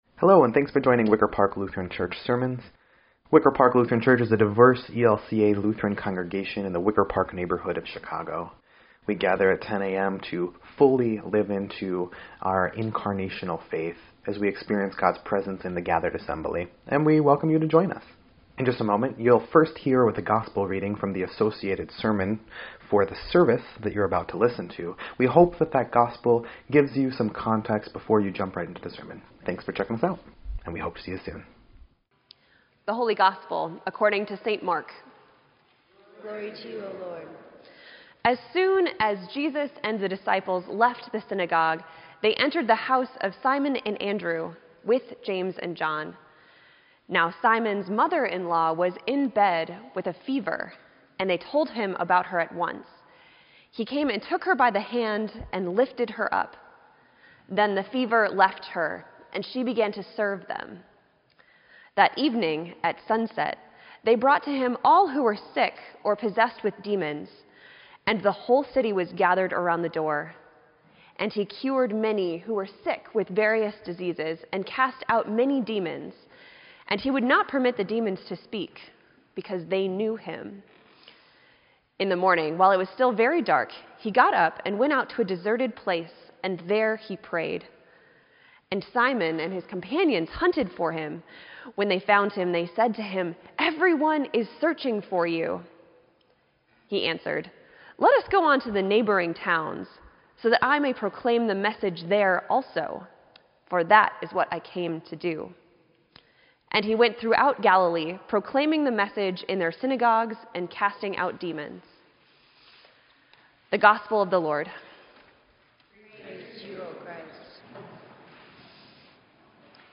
Sermon_2_4_18_EDIT.mp3